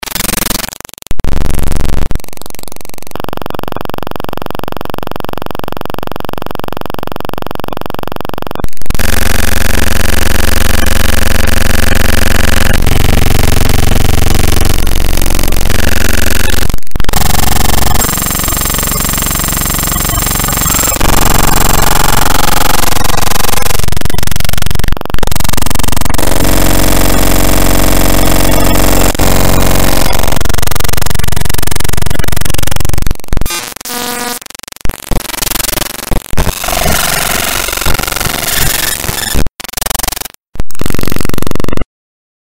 Звуки телевизора
На этой странице собраны разнообразные звуки телевизора: от характерного писка при включении до статичных помех и переключения каналов.